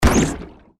diving_game_hit.ogg